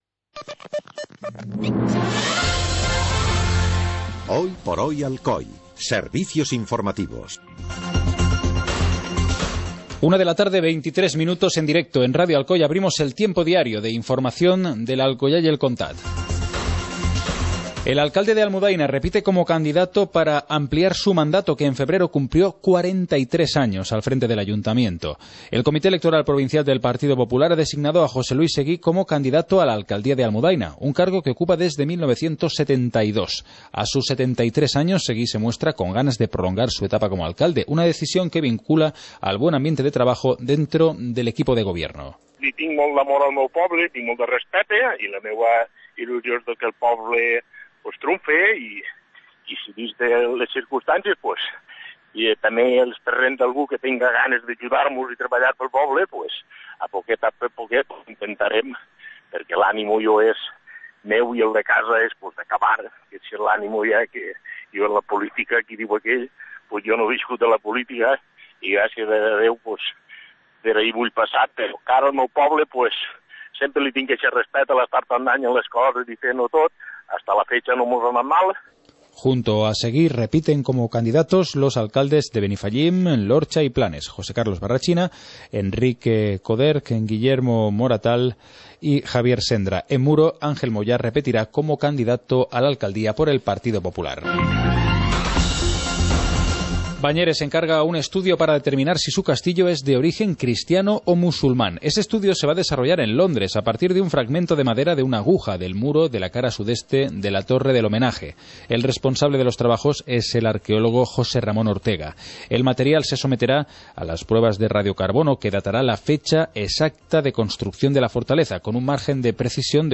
Informativo comarcal - lunes, 30 de marzo de 2015